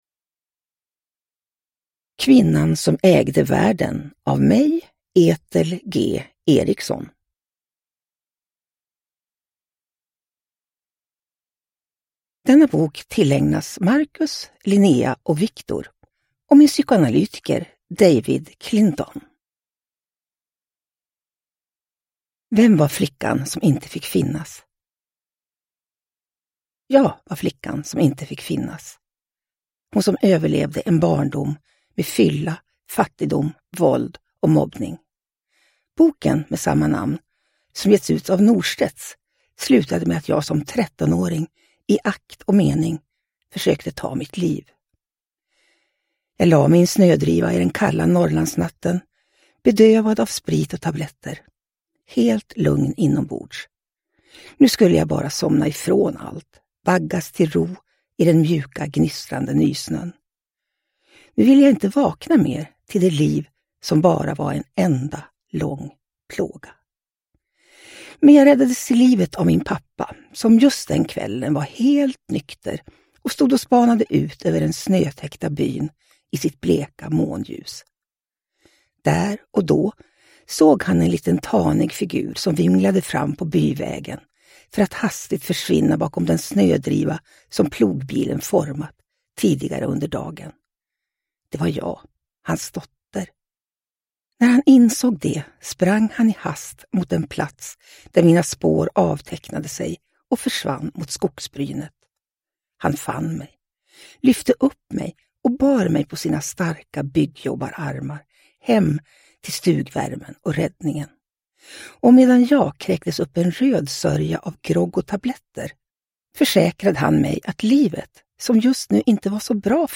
Kvinnan som ägde världen – Ljudbok – Laddas ner